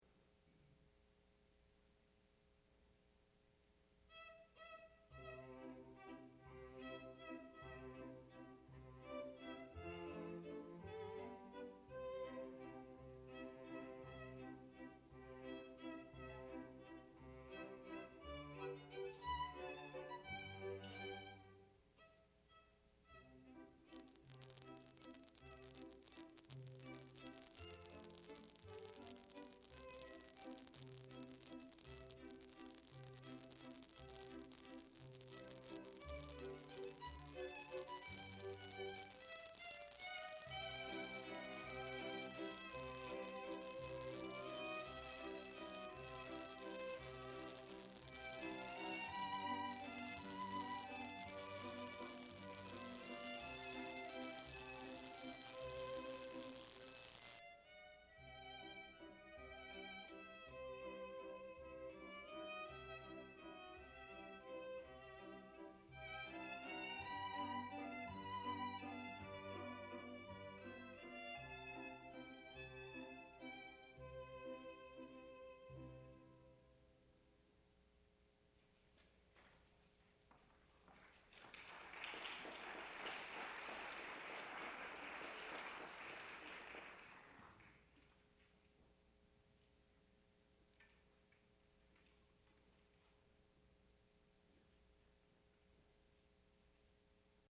Waltz
sound recording-musical
classical music
flute